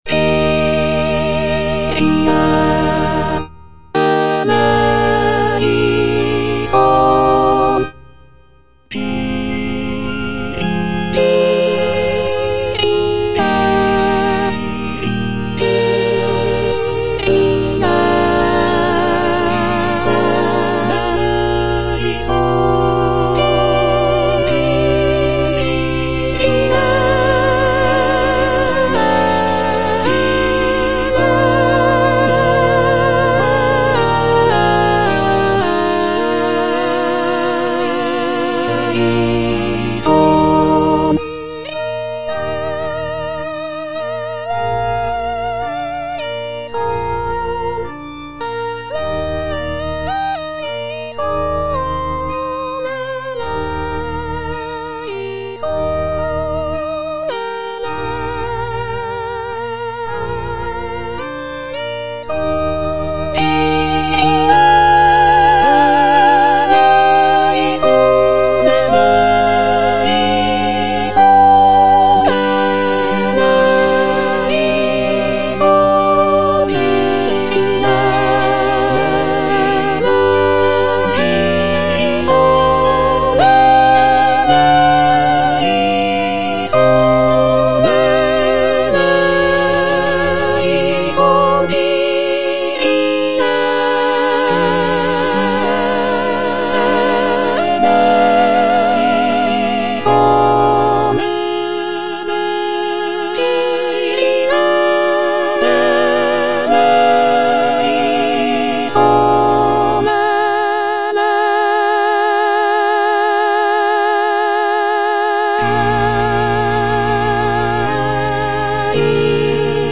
Soprano (Emphasised voice and other voices) Ads stop
sacred choral work